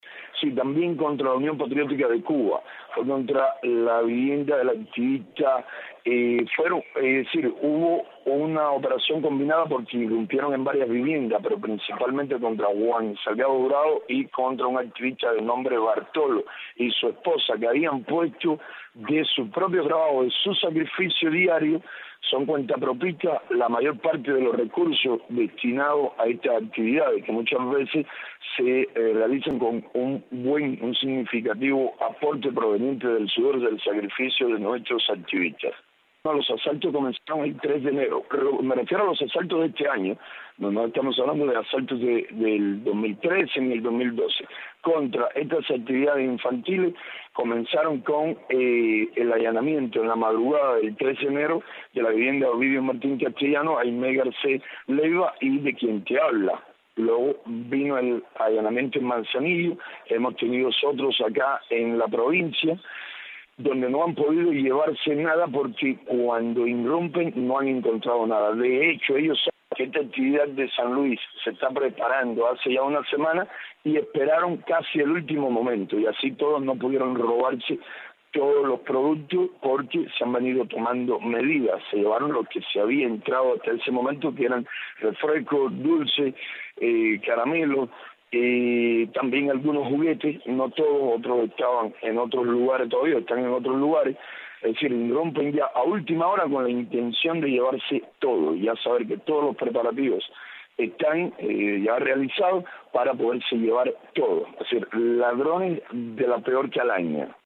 Declaraciones de José Daniel Ferrer desde Santiago de Cuba.